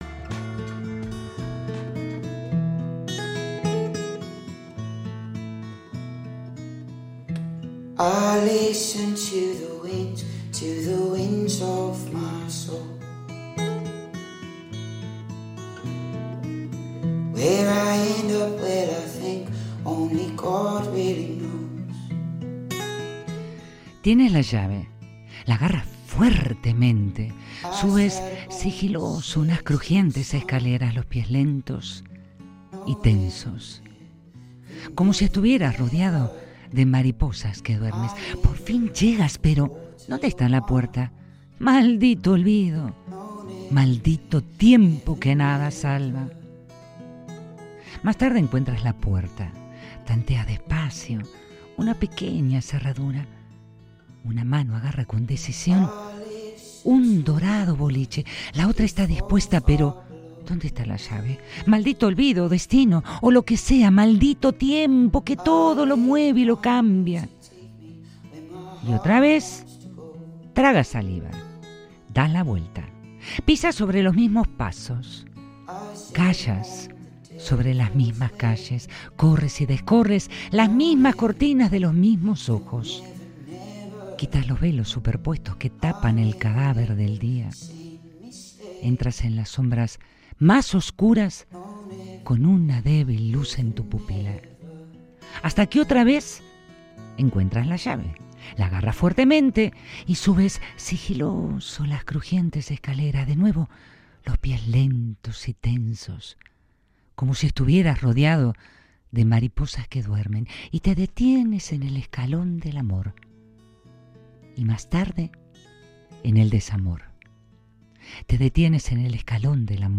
Lectura compartida en La Fiaca